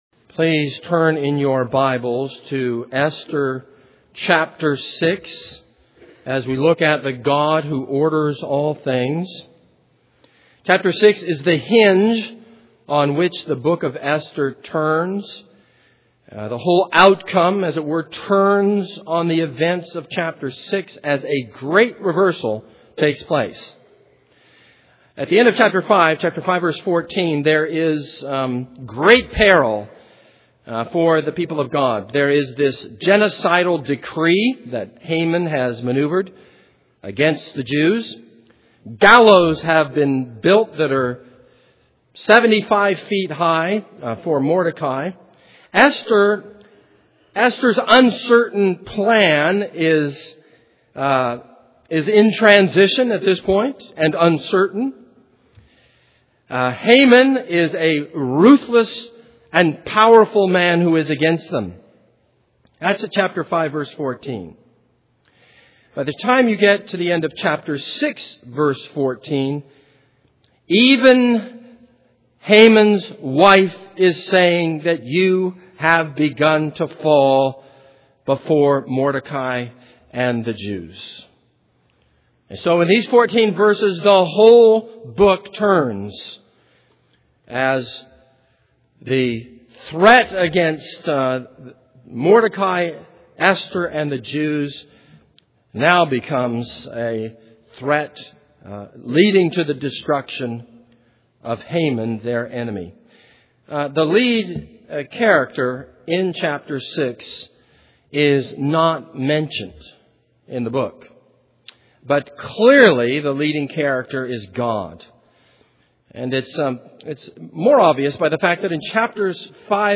This is a sermon on Esther 6.